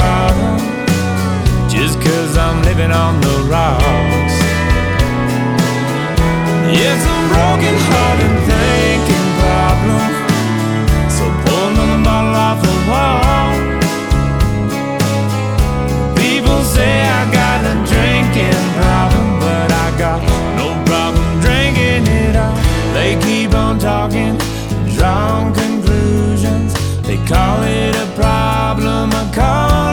• Country
American country music band